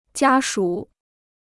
家属 (jiā shǔ) Free Chinese Dictionary